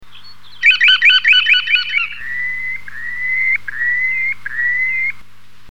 Bécasseau de Temminck, calidris temmincki